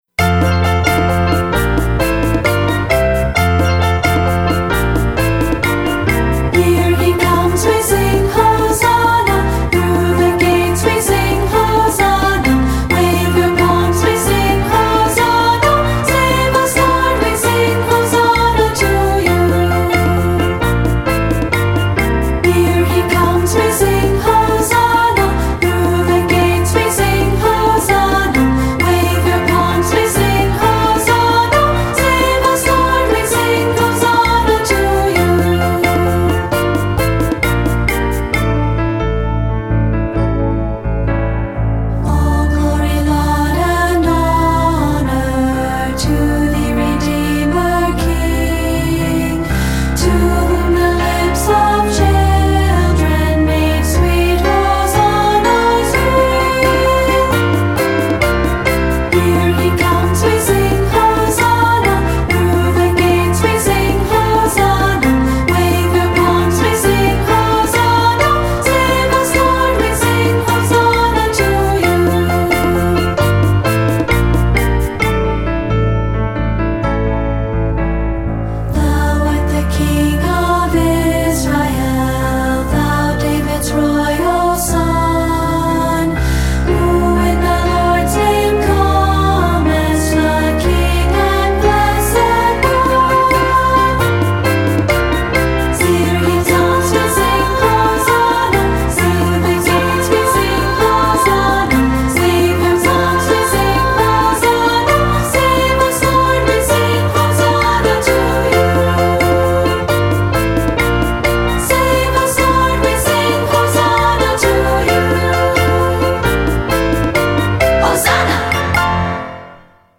Voicing: Unison/2-Part, opt. Percussion